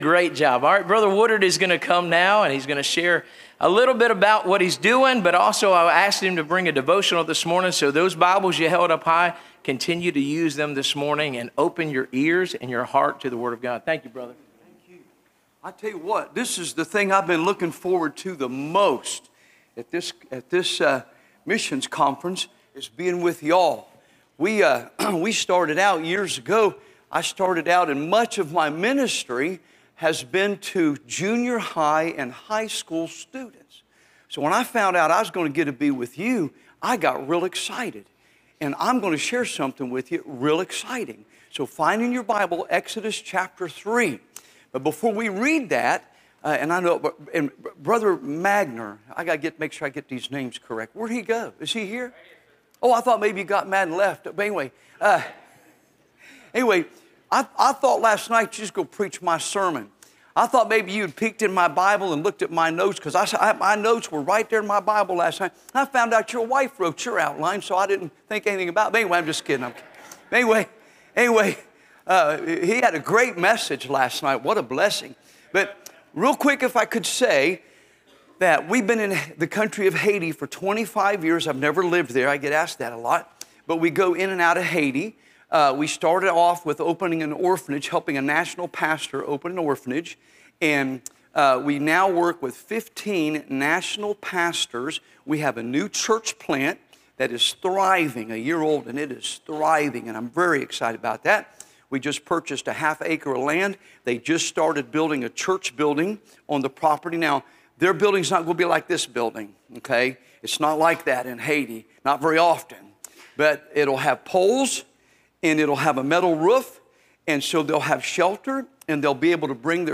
Series: (Missions Conference 2025)
Preacher